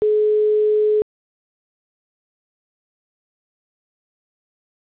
ringback_br.wav